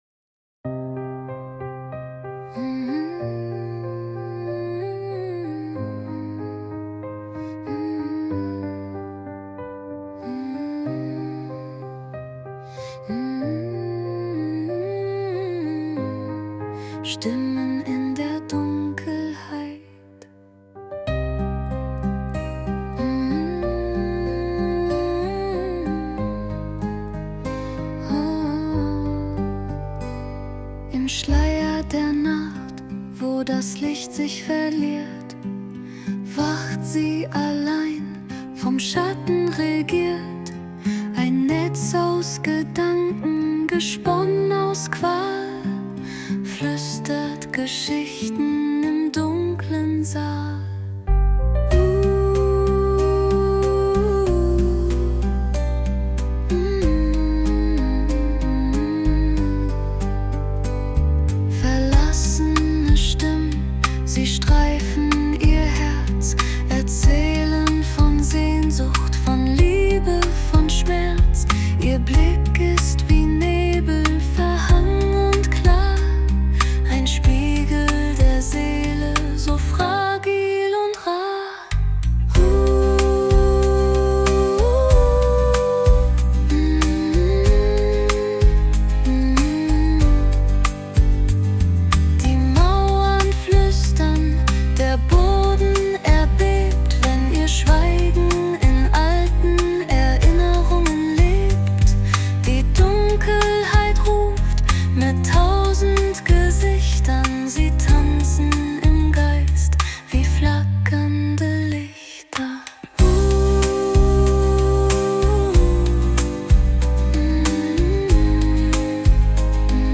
2025 Genre: Mystery Lyrik